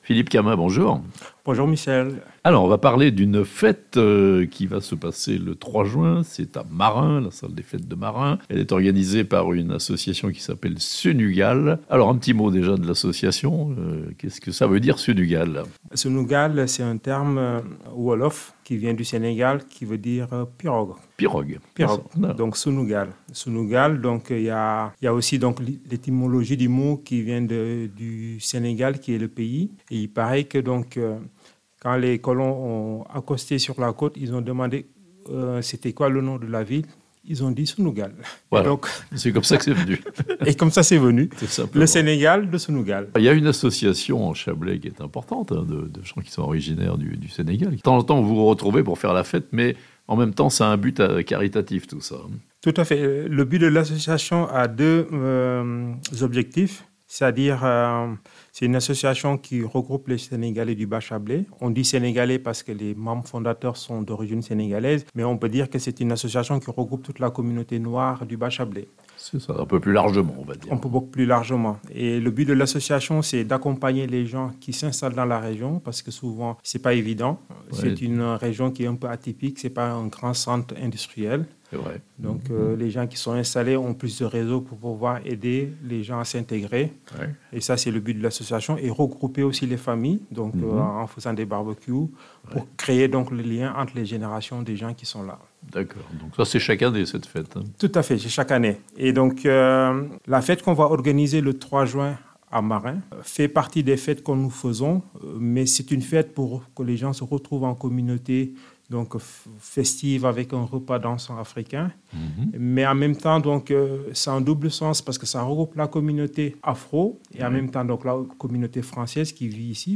Le Sénégal et l'Afrique en fête à Marin le samedi 3 juin (interview)